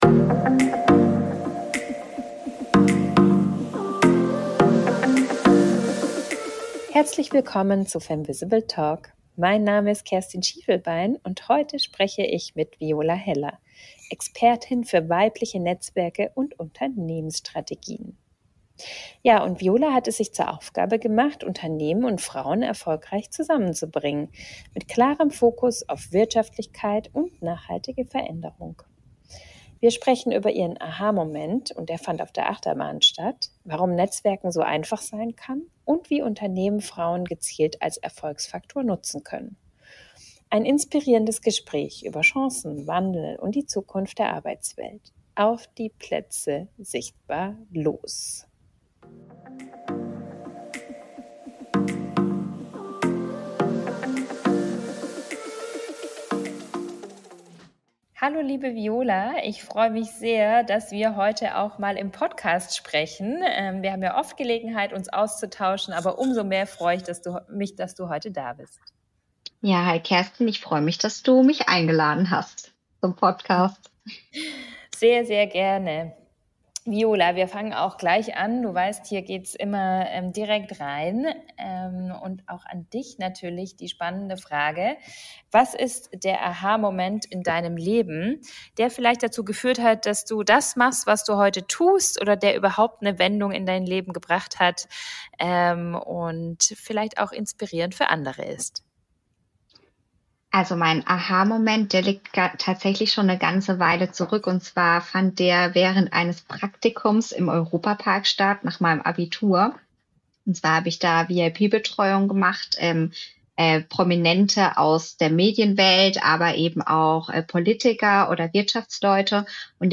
Welche wirtschaftlichen Vorteile echte Diversity bringt. Warum viele Netzwerke scheitern – und wie es besser geht. Ein Gespräch über Chancen, Wandel und die Zukunft von Frauen in der Wirtschaft.